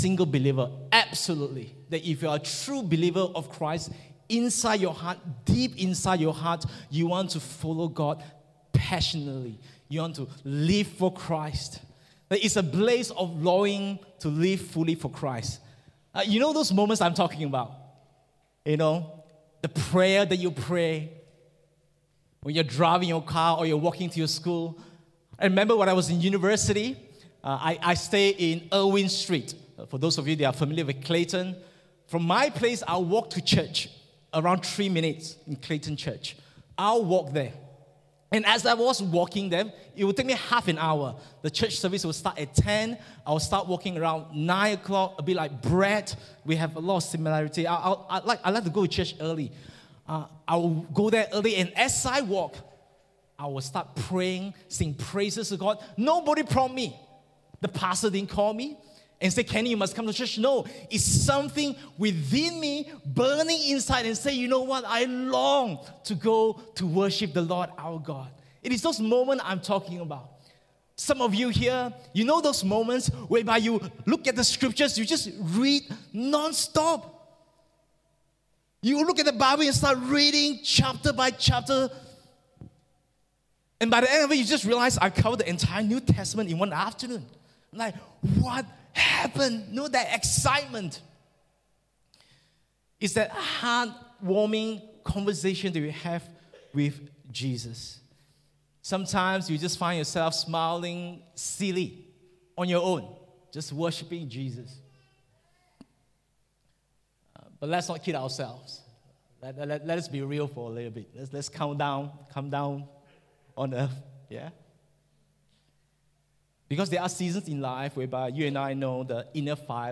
English Sermons | Casey Life International Church (CLIC)
English Worship Service 15 Oct 2023